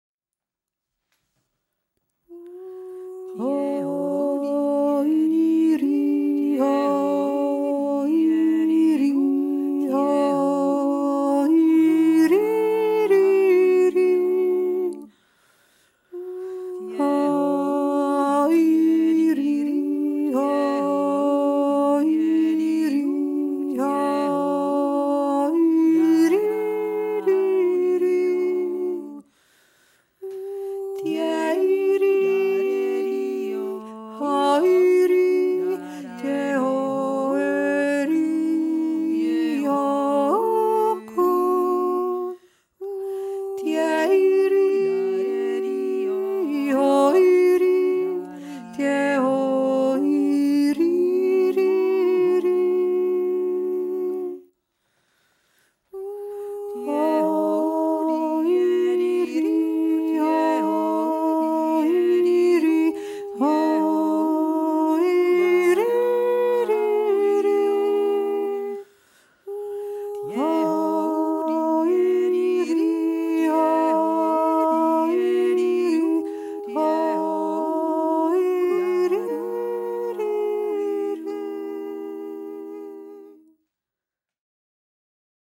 3. Stimme